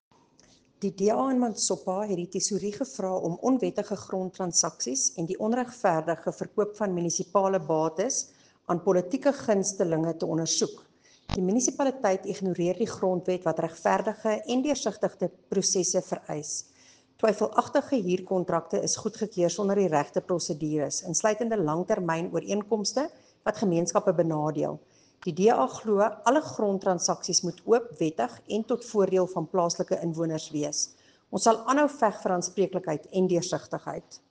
Afrikaans soundbite by Cllr Erica Moir.